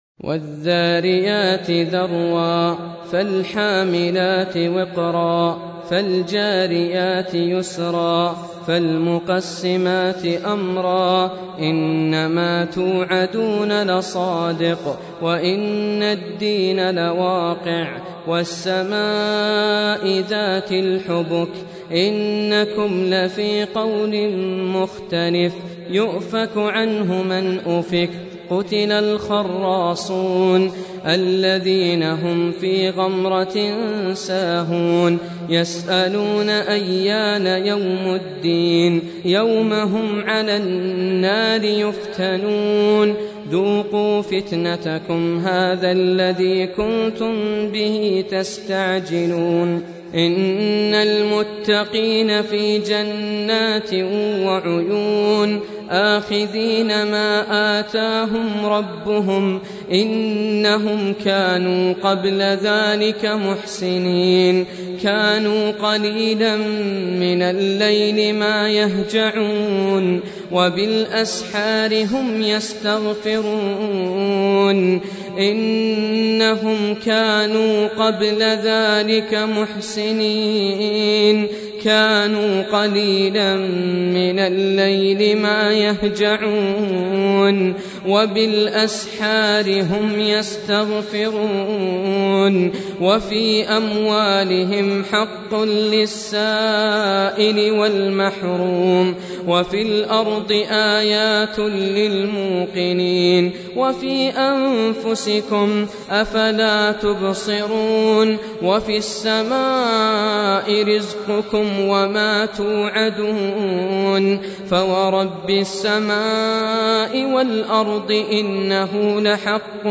Surah Repeating تكرار السورة Download Surah حمّل السورة Reciting Murattalah Audio for 51. Surah Az-Z�riy�t سورة الذاريات N.B *Surah Includes Al-Basmalah Reciters Sequents تتابع التلاوات Reciters Repeats تكرار التلاوات